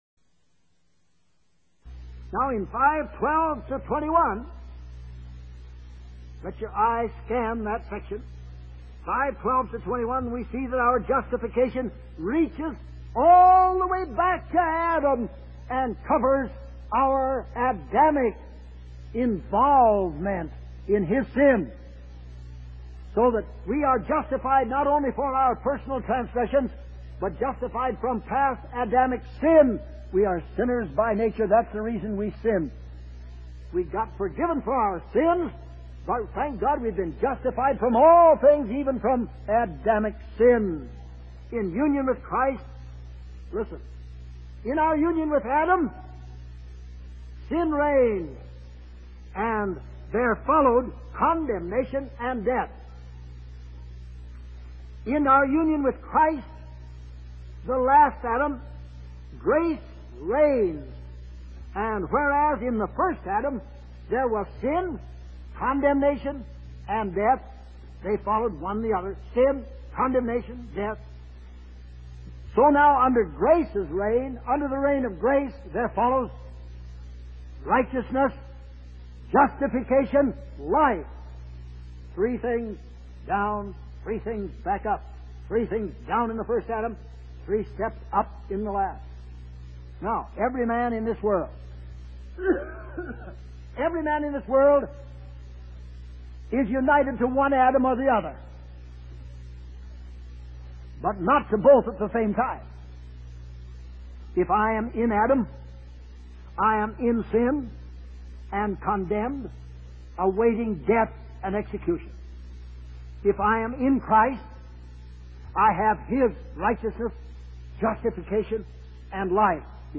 In this sermon, the preacher emphasizes the importance of faith in the Christian life. He explains that faith should be based on facts, not feelings. The main fact to focus on is the believer's union with Christ in his death and resurrection.